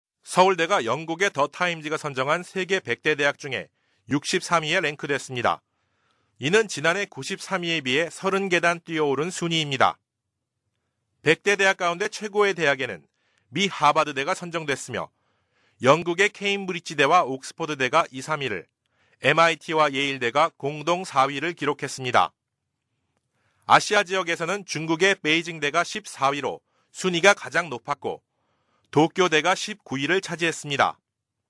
Sprecher koreanisch für TV / Rundfunk / Industrie.
Kein Dialekt
Sprechprobe: Werbung (Muttersprache):